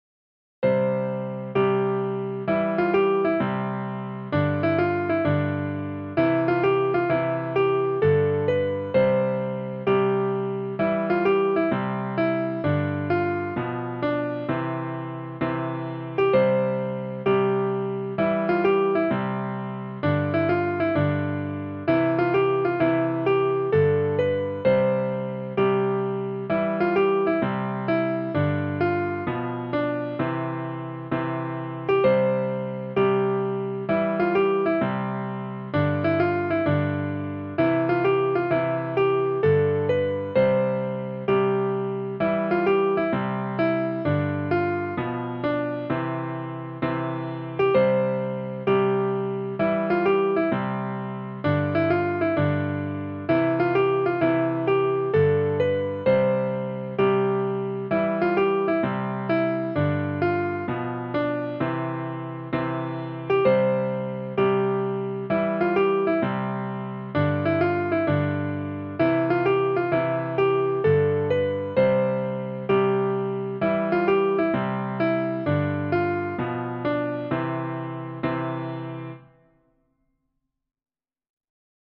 a children's song from Scotland
for piano